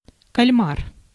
Ääntäminen
Synonyymit pieuvre Ääntäminen France: IPA: [pulp] Haettu sana löytyi näillä lähdekielillä: ranska Käännös Ääninäyte 1. кальмар {m} (kalmar) 2. осьмино́г {m} (osminóg) Suku: m .